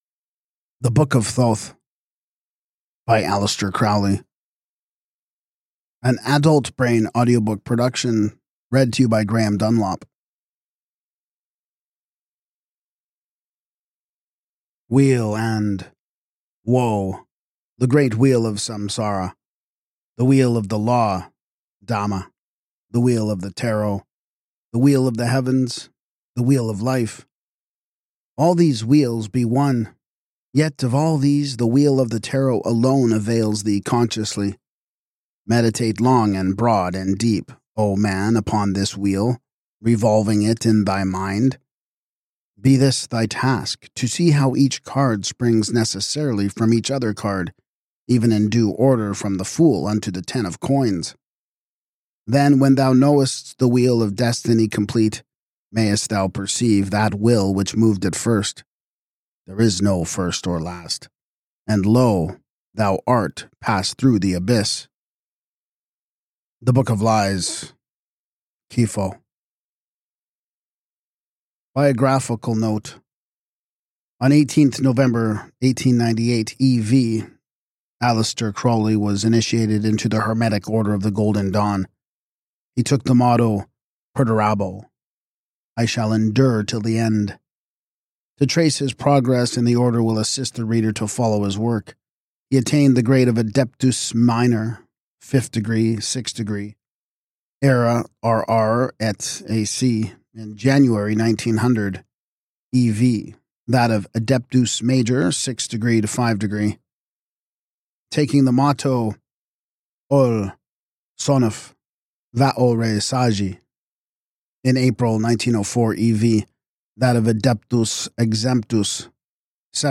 What You’ll Discover in This Audiobook: